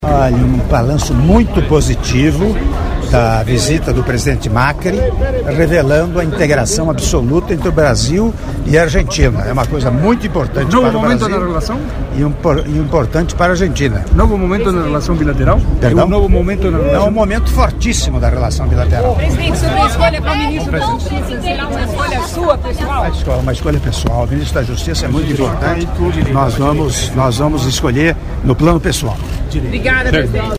Áudio da entrevista coletiva concedida pelo Presidente da República, Michel Temer, após almoço oferecido ao presidente da República Argentina, Mauricio Macri - Palácio Itamaraty (30s)